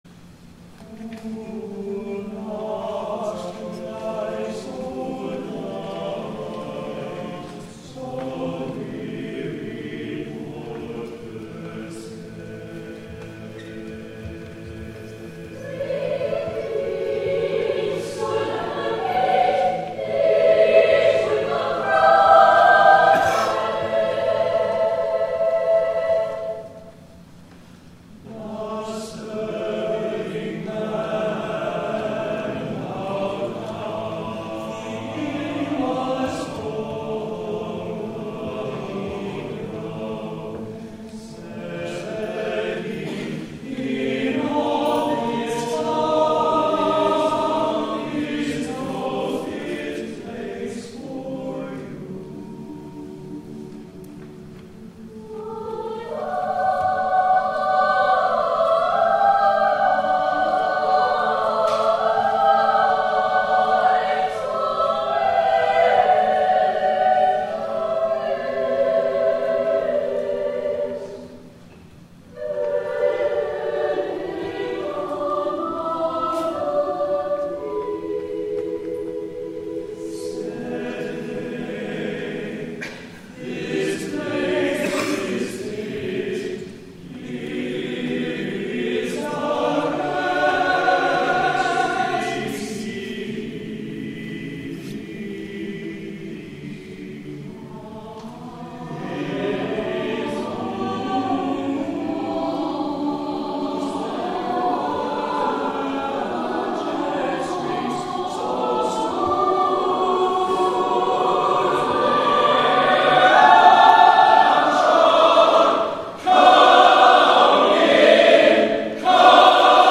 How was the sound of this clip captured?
8 P.M. WORSHIP